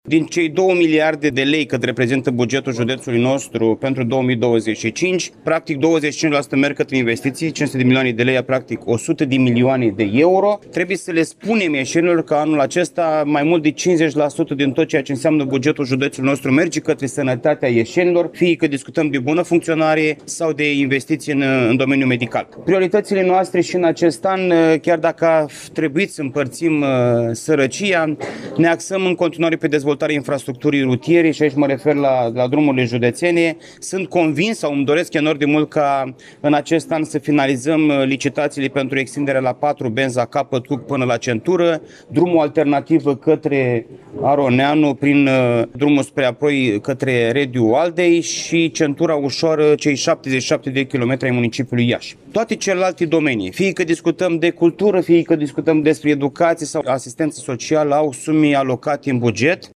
Președintele Consiliului Județean Iași, Costel Alexe, a subliniat că aproape jumătate din bugetul Iașiului este rezervat funcționării unităților sanitare: